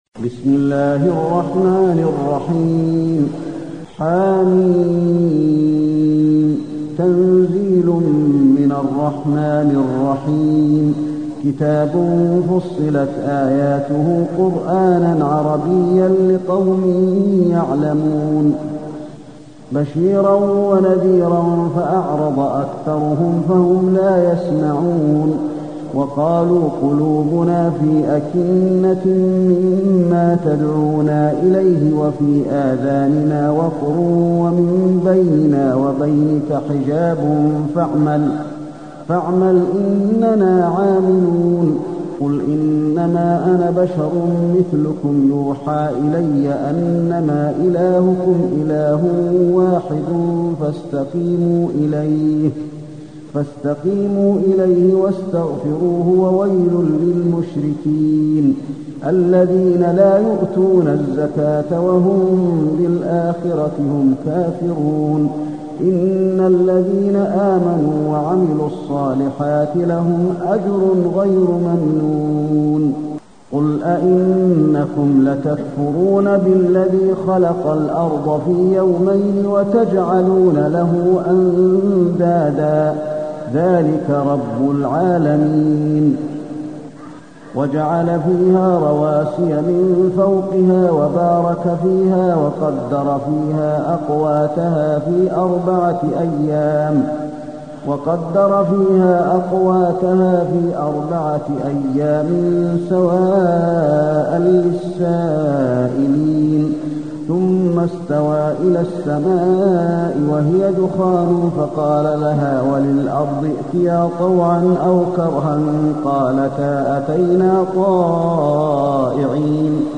المكان: المسجد النبوي فصلت